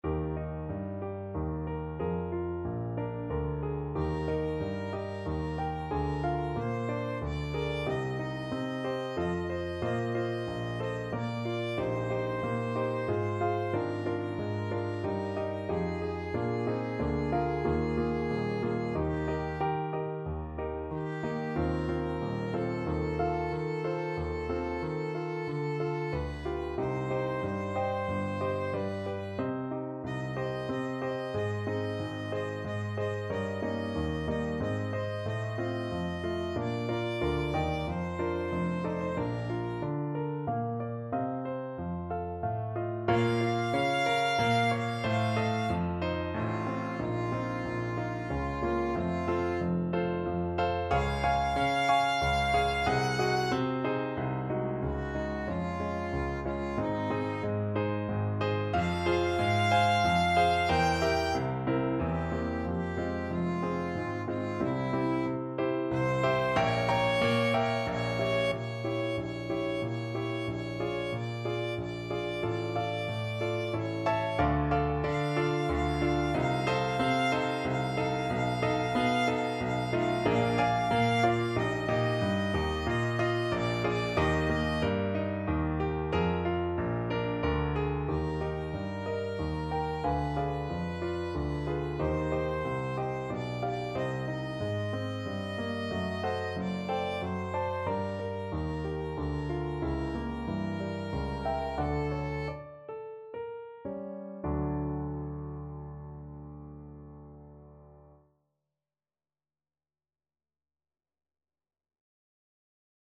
Violin version
~ = 92 Larghetto
Classical (View more Classical Violin Music)